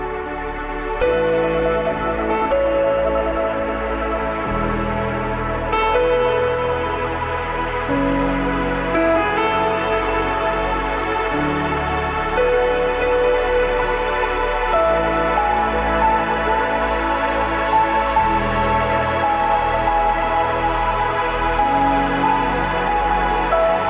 Samsung Ringtones